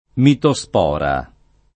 [ mito S p 0 ra ]